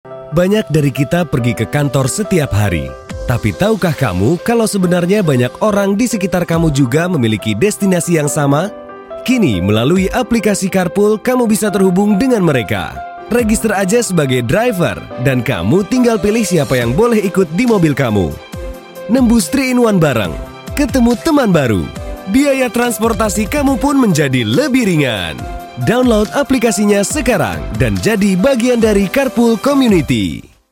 Endonezce Seslendirme
Erkek Ses